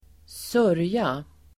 Uttal: [²s'ör:ja]